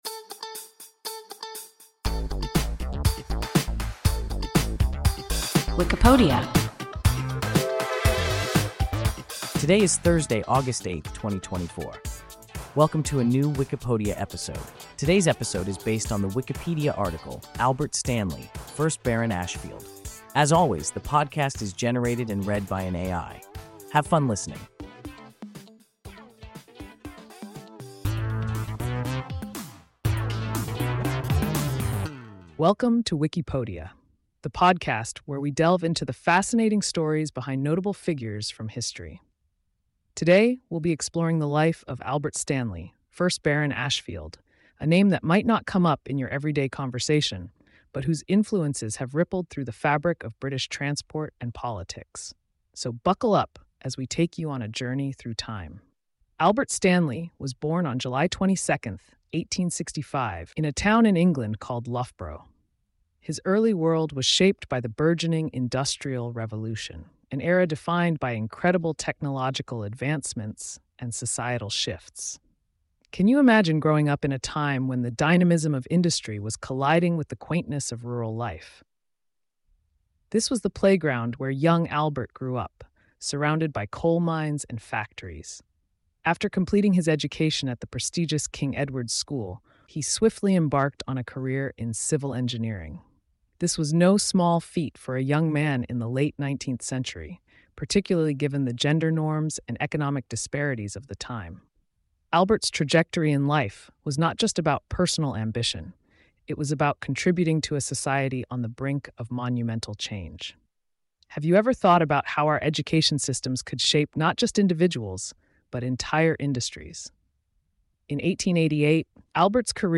Albert Stanley, 1st Baron Ashfield – WIKIPODIA – ein KI Podcast